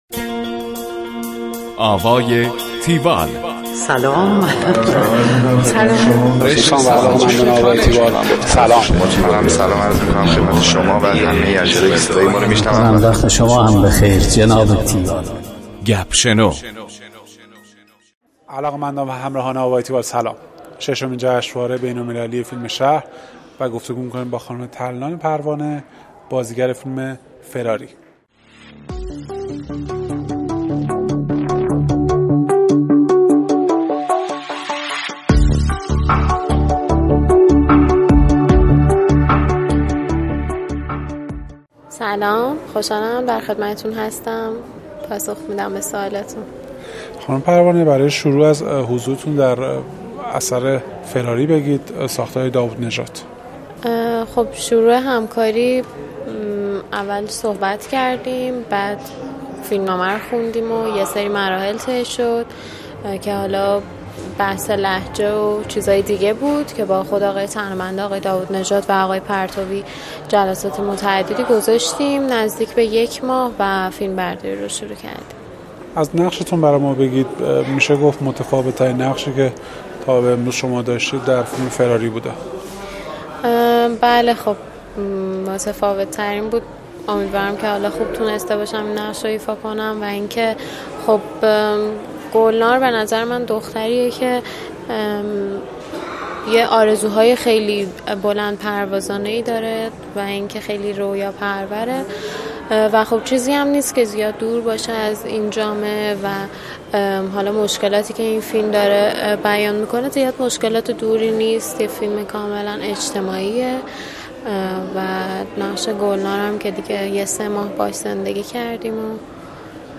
گفتگوی تیوال با ترلان پروانه
tiwall-interview-tarlanparvaneh.mp3